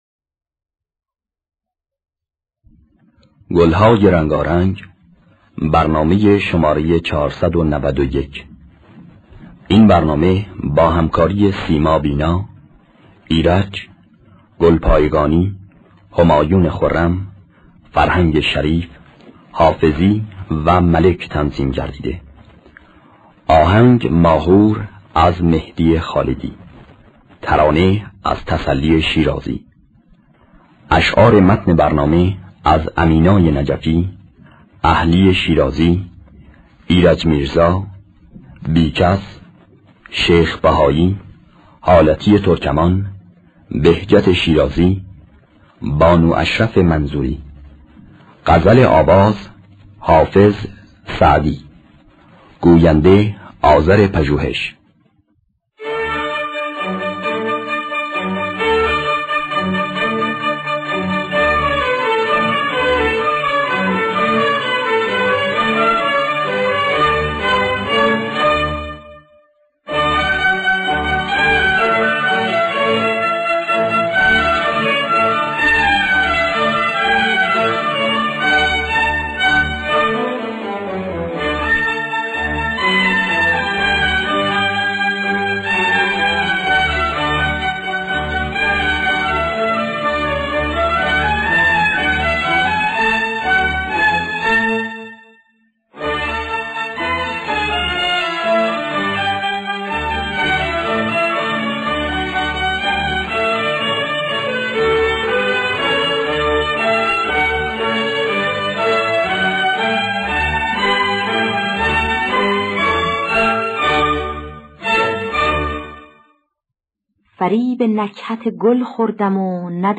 خوانندگان: سیما بینا ایرج اکبر گلپایگانی